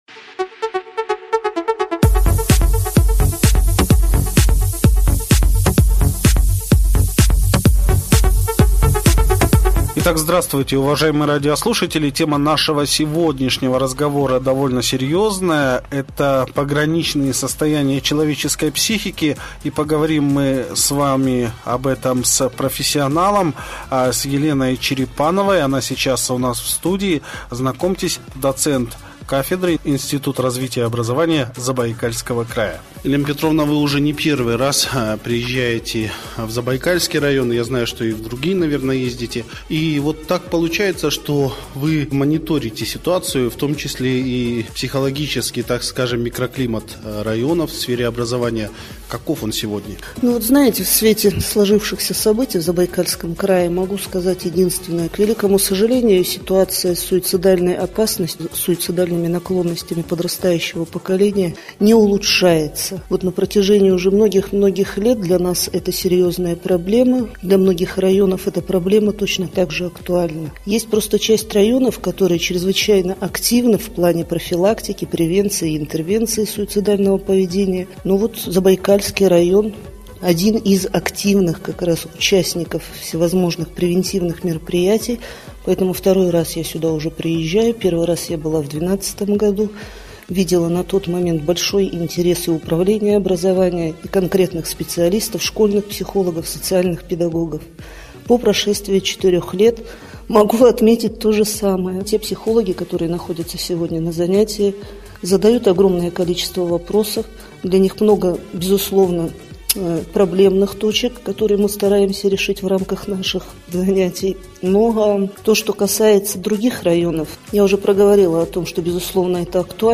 Что можно противопоставить этому. как удержать человека от опрометчивого поступка. Разговариваем с психологом